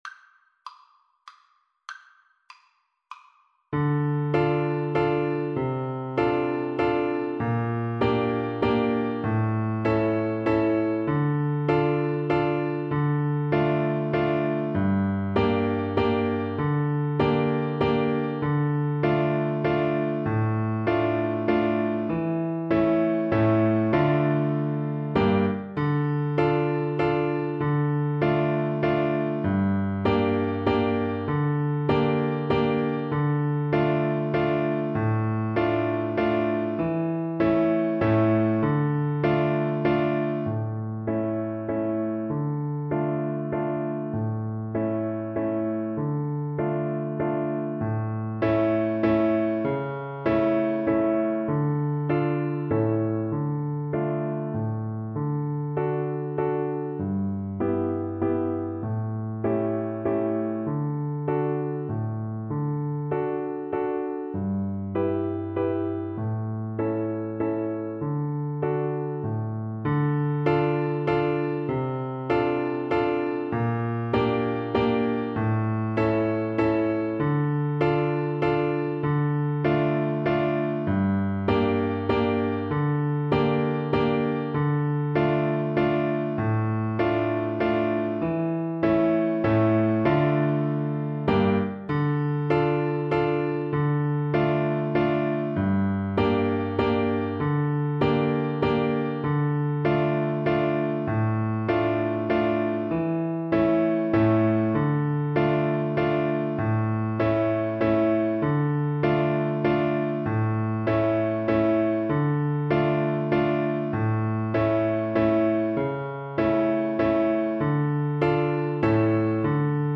Play (or use space bar on your keyboard) Pause Music Playalong - Piano Accompaniment Playalong Band Accompaniment not yet available transpose reset tempo print settings full screen
Flute
Traditional Music of unknown author.
D minor (Sounding Pitch) (View more D minor Music for Flute )
3/4 (View more 3/4 Music)
Molto allegro =c.140